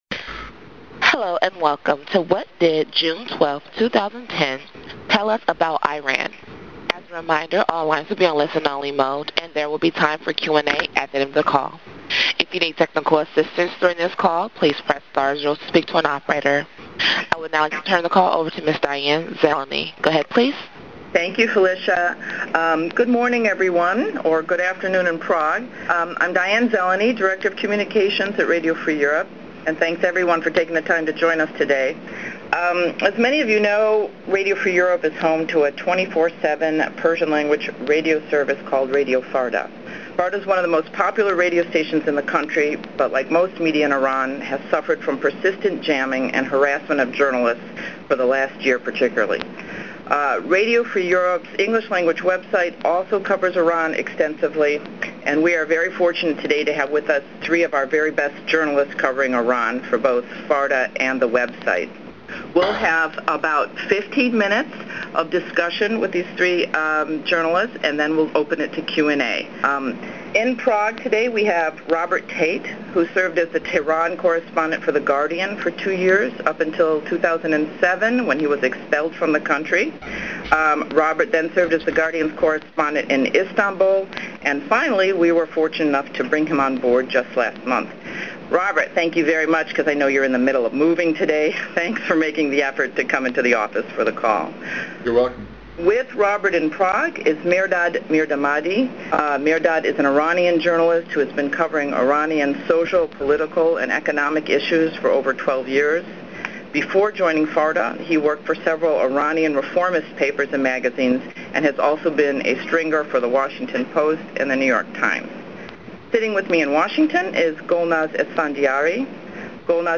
Audio of conference call